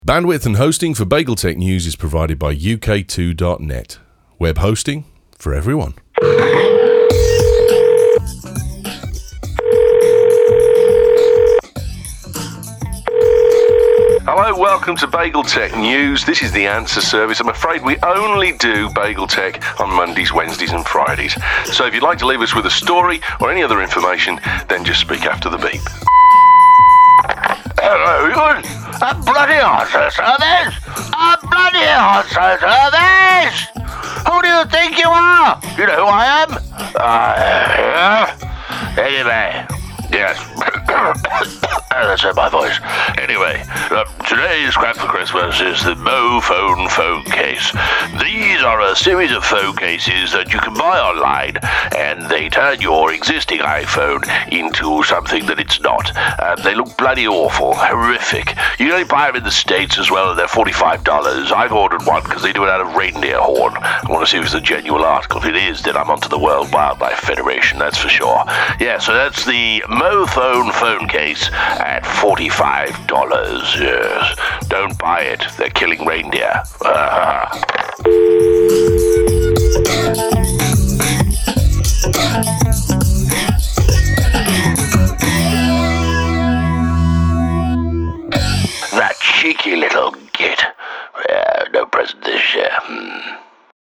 2nd Attempt Sorry...Santa Leaves us his Crap for Christmas on the Answer Service - (By the way, incase your seriously minded...a) Get a Life, b) Stop working in Local Government and c) I'm pretty sure they DON'T kill reindeer)